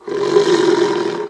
/hl2/sound/npc/antlion_guard/near/
peek_look.ogg